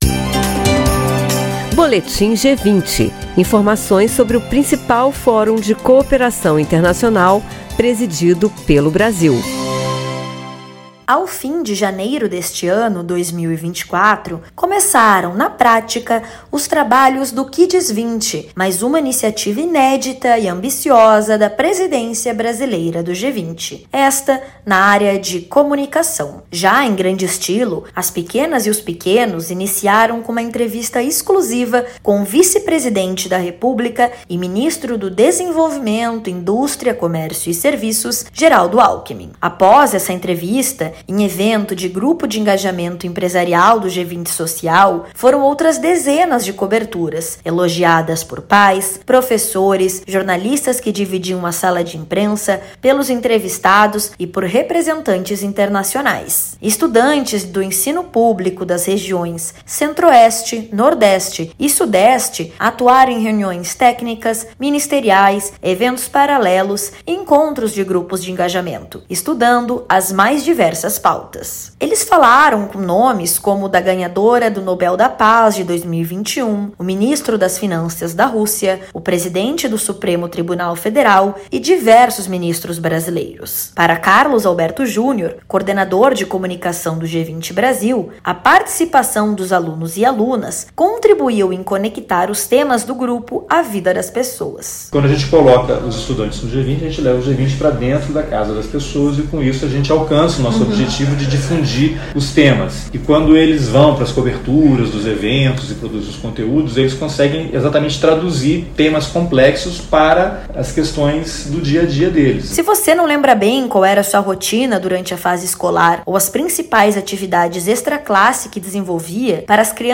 Na abertura da semana de debates sobre o enfrentamento às mudanças do clima no G20, João Paulo Capobianco, secretário executivo do MMA, ressaltou a importância da agenda climática no evento e instou os países desenvolvidos a intensificarem o financiamento para combater a crise climática. Ouça a reportagem e saiba mais.